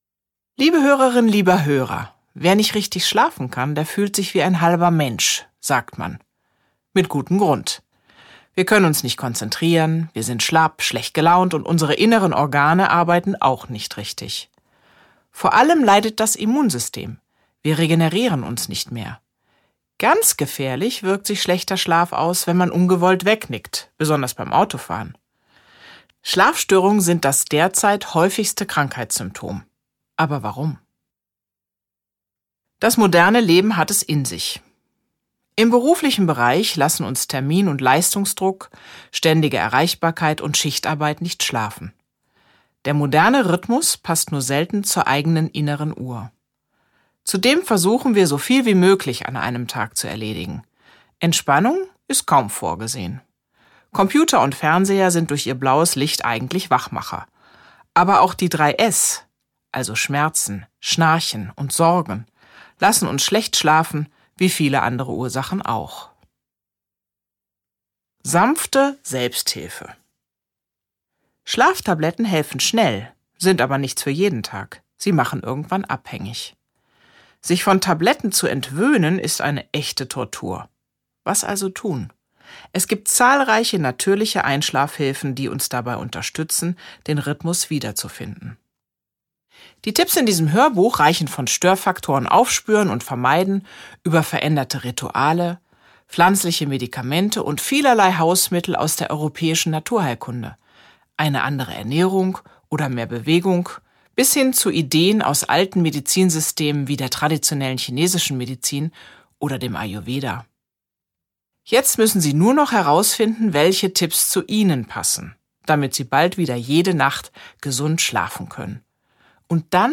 Mein kleines Hörbuch vom guten Schlaf Einschlaf- und Durchschlafstörungen natürlich behandeln Franziska Rubin (Autor) Franziska Rubin (Sprecher) Audio-CD 2023 | 2.